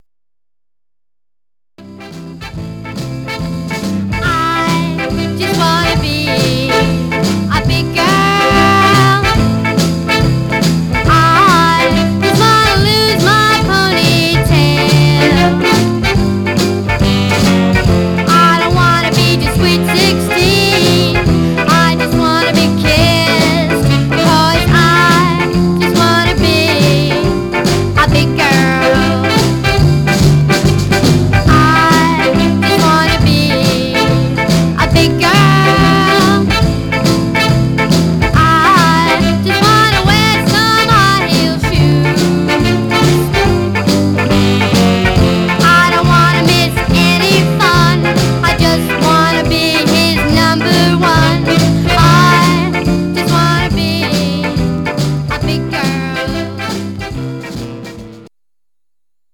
Mono
Teen